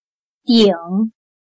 /Dǐng/parte superior